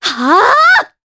Ophelia-Vox_Casting4_jp.wav